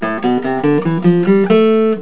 The natural minor scale contains half steps between notes 2 to 3 and notes 5 to 6.
ScaleMin.au